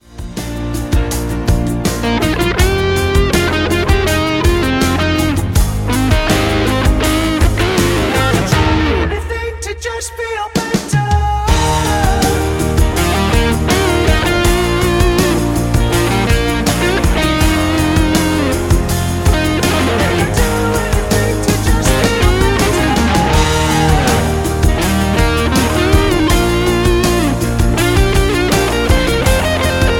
MPEG 1 Layer 3 (Stereo)
Backing track Karaoke
Rock, 2000s